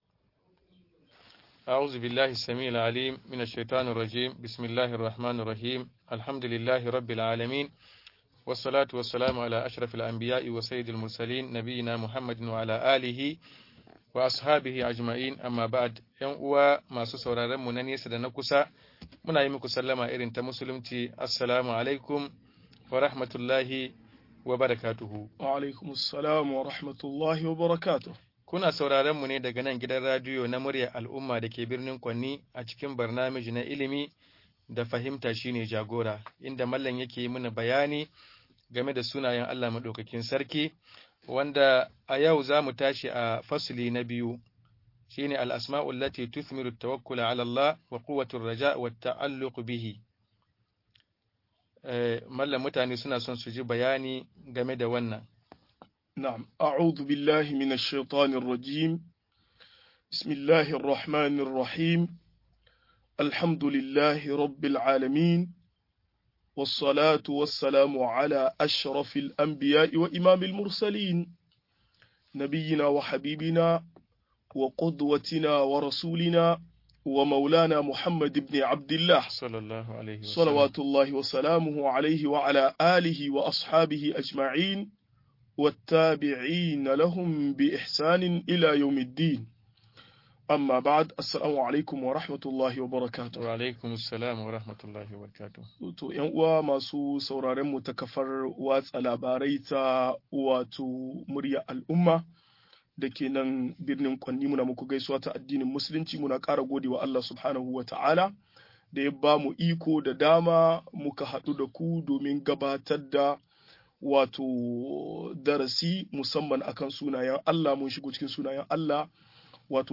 Sunayen Allah da siffofin sa-07 - MUHADARA